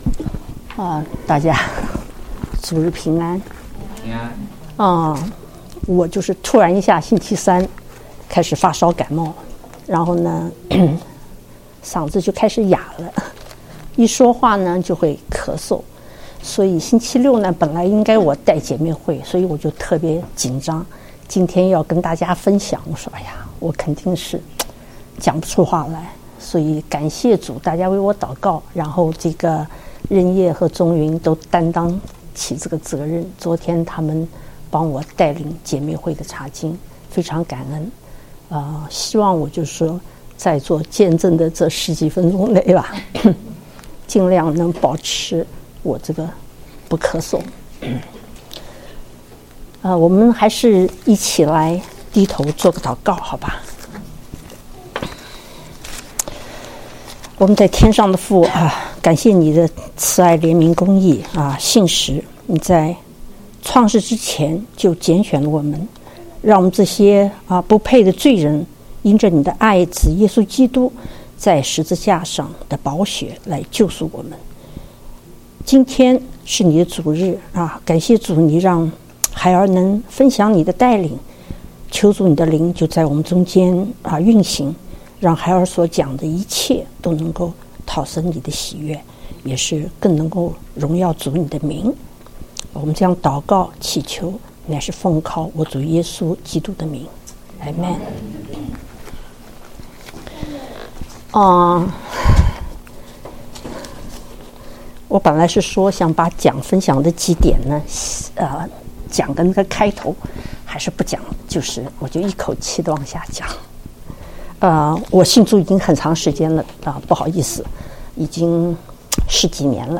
見證分享